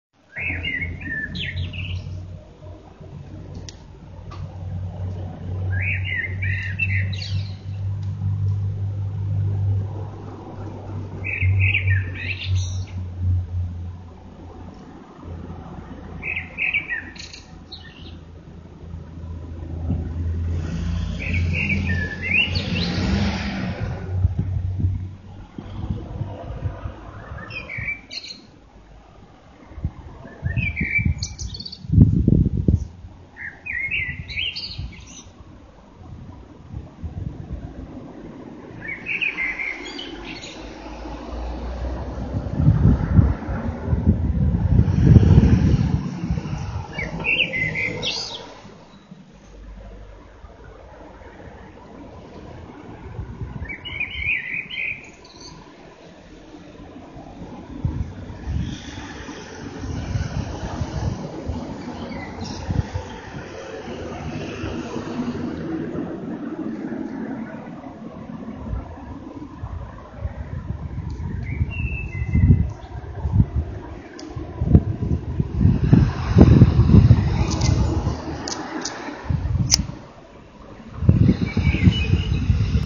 Tag: śpiew
Skro doszedłem do domu z centrum miasta cały i w dobrym stanie, to znaczy, że nie byłem jakoś szczególnie urżnięty, ale nawet gdybym był, nie przegapiłbym na pewno tego (spotkaliśmy się w ruchliwym miejscu miasta, na rondzie, tym najstarszym, więc w tle słychać odgłosy ulicy):
Ja to się przynajmniej nie drę ludziom pod oknami wniebogłosy, wracając nocą z imprezy 😛
Kos-nocą.mp3